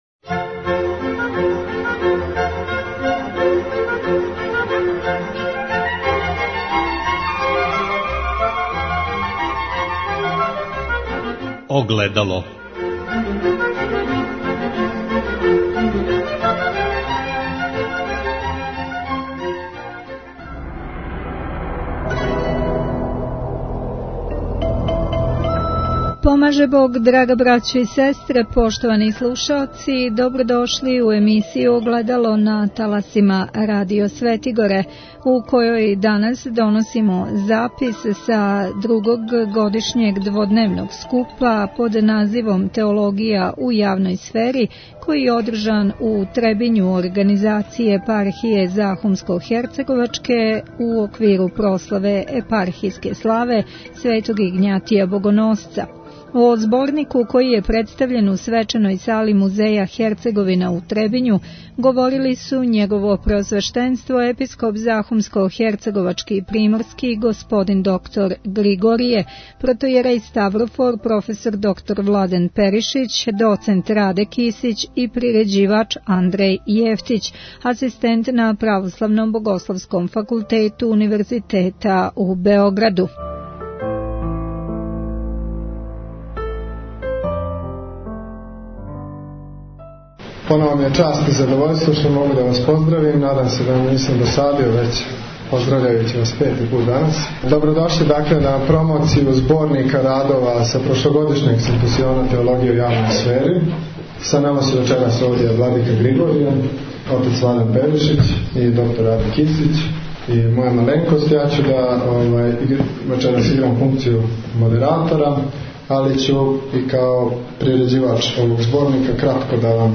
У Требињу је одржан и други годишњи дводневни скуп под називом „Теологија у јавној сфери“, који Епархија захумско-херцеговачка организује у оквиру прославе епархијске славе, Светог Игњатија Богоносца.